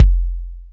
KIK-Humble Kick.wav